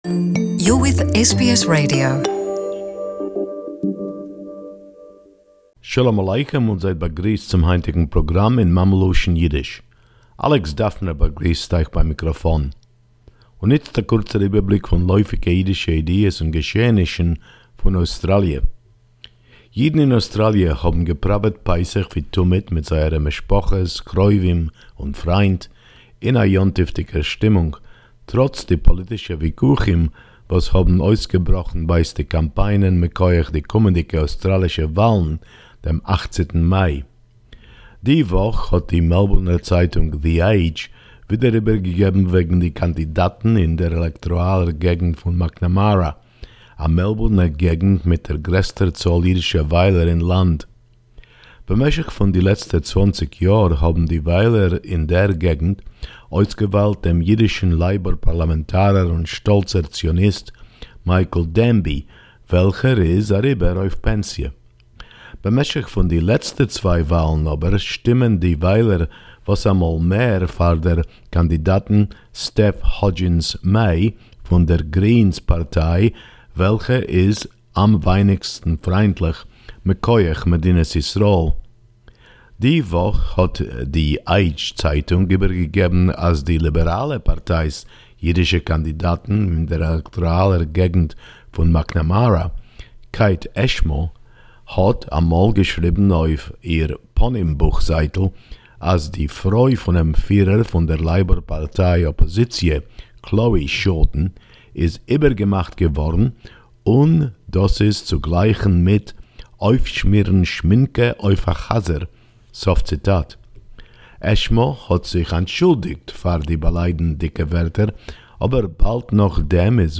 Yiddish News Of The week